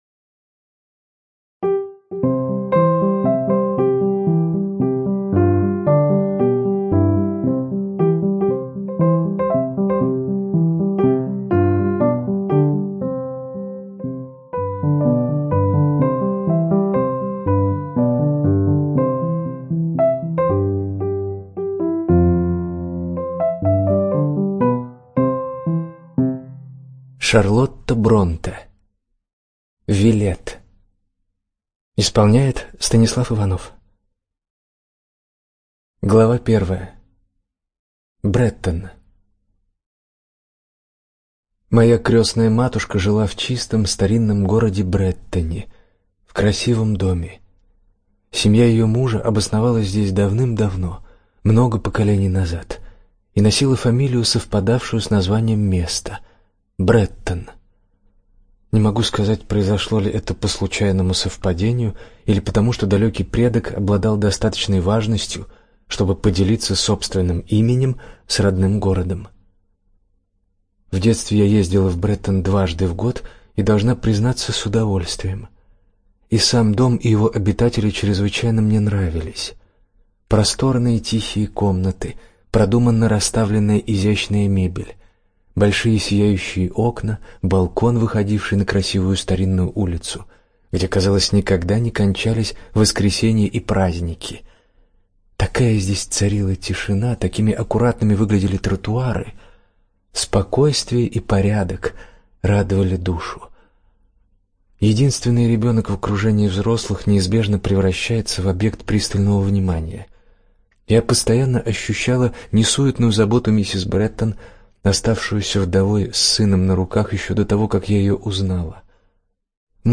ЖанрЛюбовная проза, Классическая проза